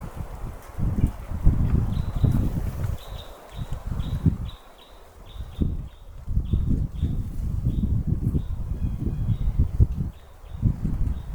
Krustknābis Loxia sp., Loxia sp.
Skaits6
Примечания/pārlido ar saucieniem, apsēžas eglēs uz mērķtiecīgu GLAPAS provokāciju.